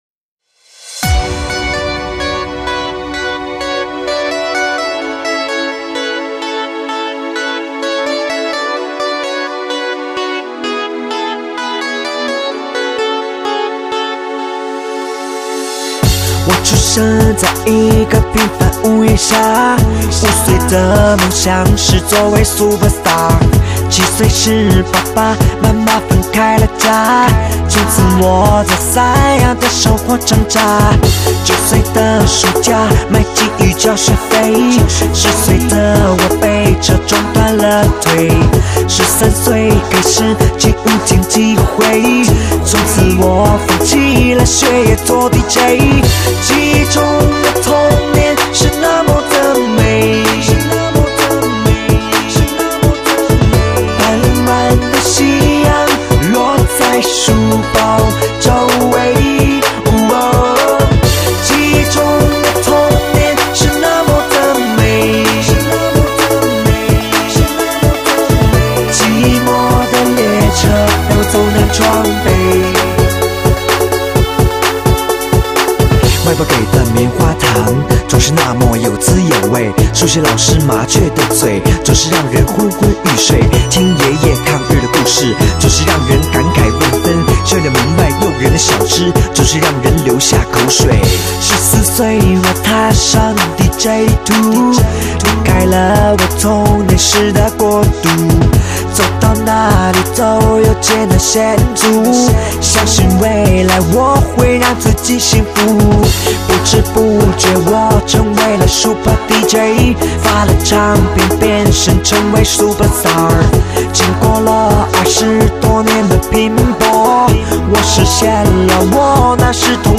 唱片类型：电音炫音